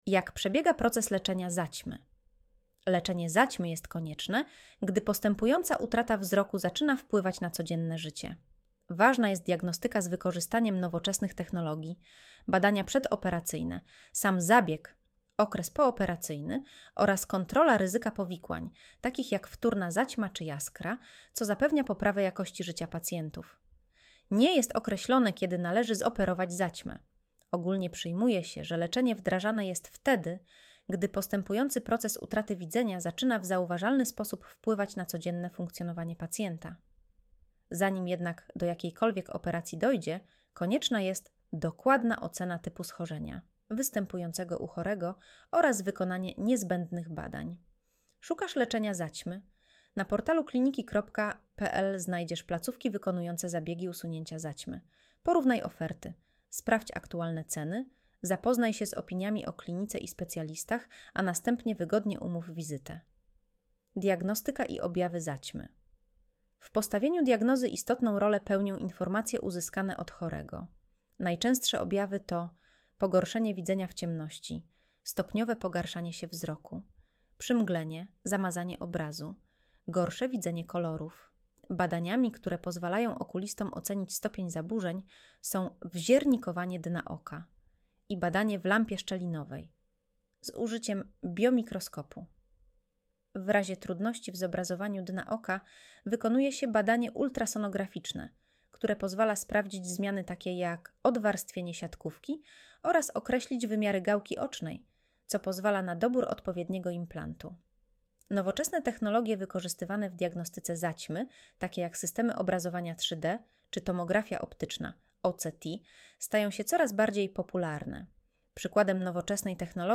Audio wygenerowane przez AI, może zawierać błędy